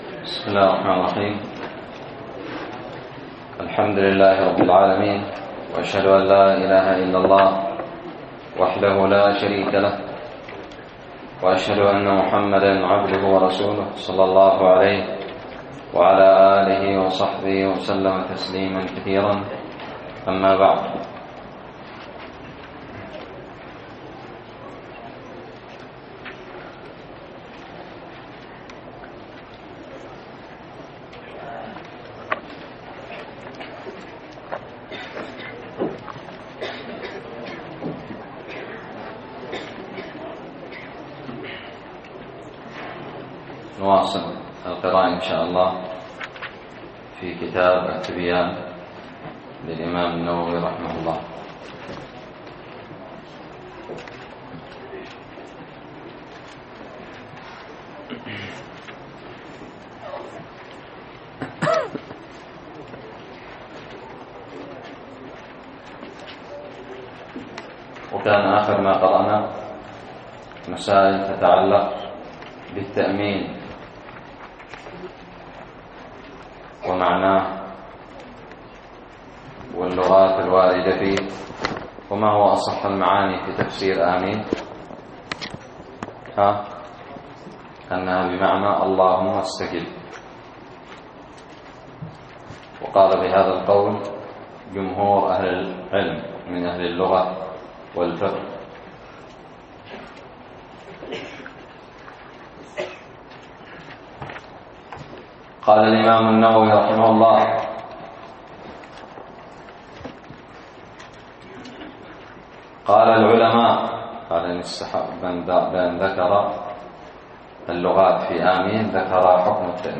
الدرس السادس والعشرون من شرح كتاب التبيان في آداب حملة القرآن
ألقيت بدار الحديث السلفية للعلوم الشرعية بالضالع